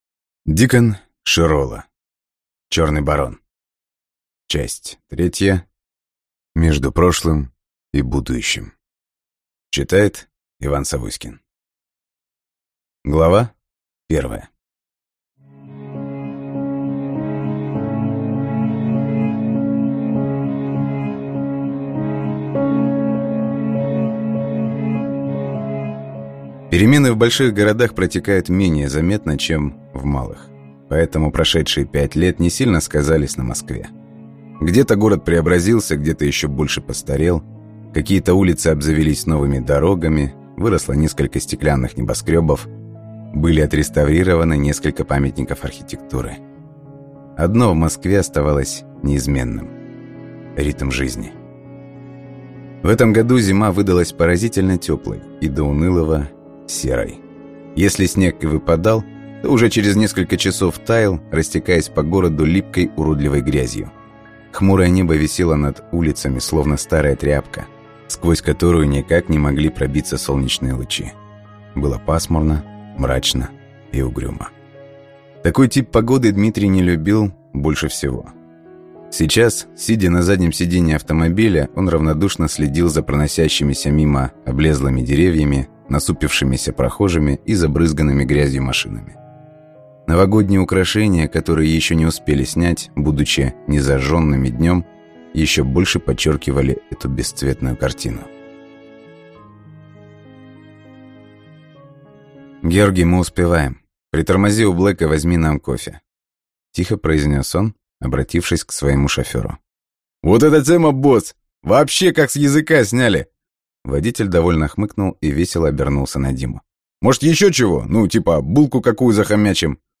Аудиокнига Между прошлым и будущим | Библиотека аудиокниг